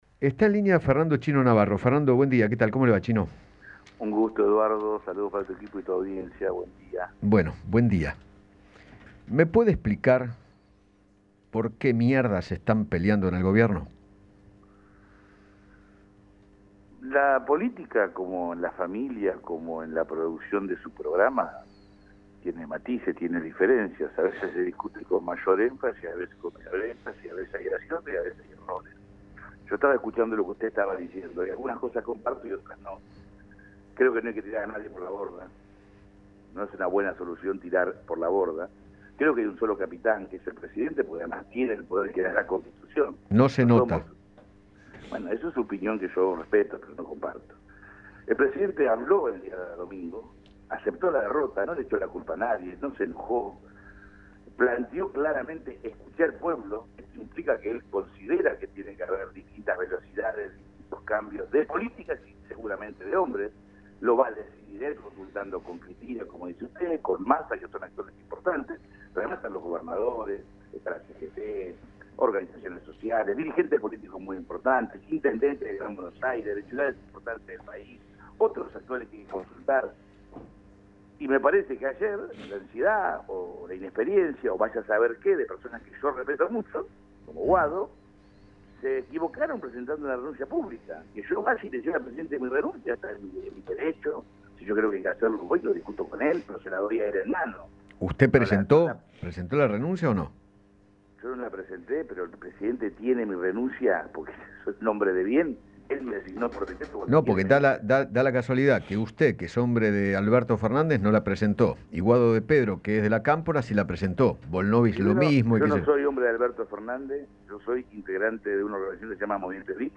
“Chino” Navarro, secretario de Relaciones Parlamentarias de la Jefatura de Gabinete, habló con Eduardo Feinmann acerca de las masivas renuncias de funcionarios del Gobierno y sostuvo que “no hay que tirar a nadie por la borda”.